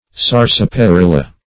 Sarsaparilla \Sar`sa*pa*ril"la\, n. [Sp. zarzaparrilla; zarza a